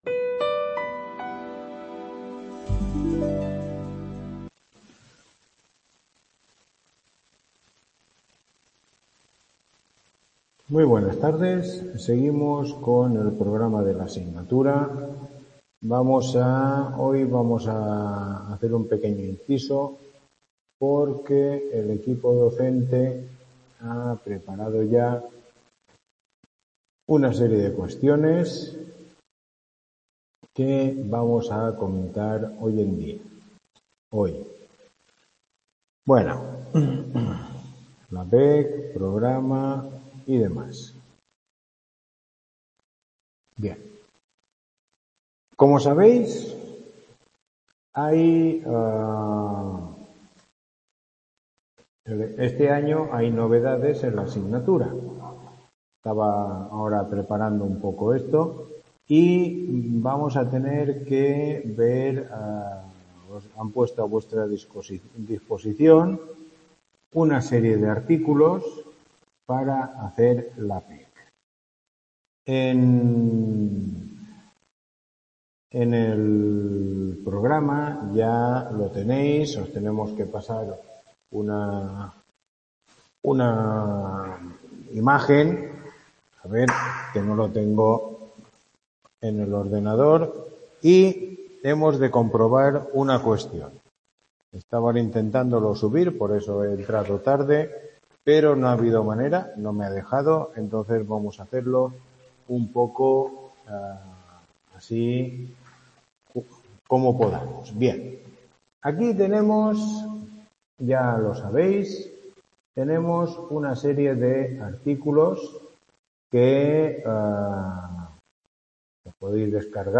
Tutoría 05